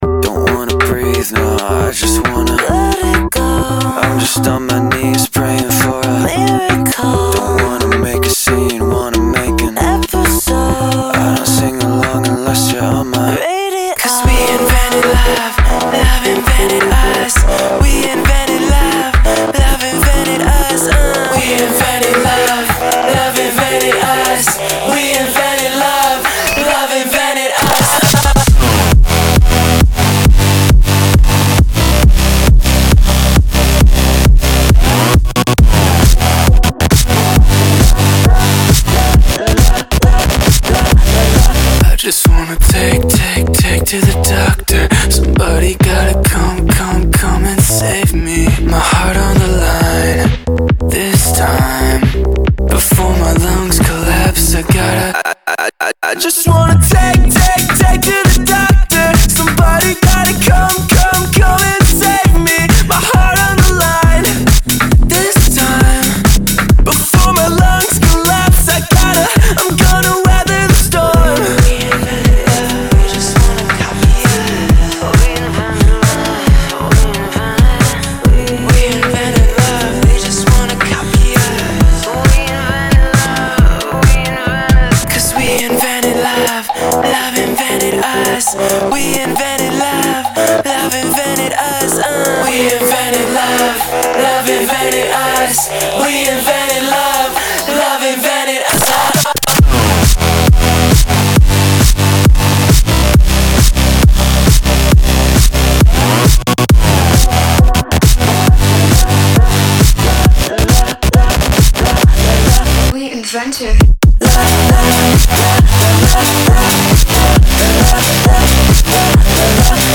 BPM135-135
Audio QualityPerfect (High Quality)
Hyperpop song for StepMania, ITGmania, Project Outfox
Full Length Song (not arcade length cut)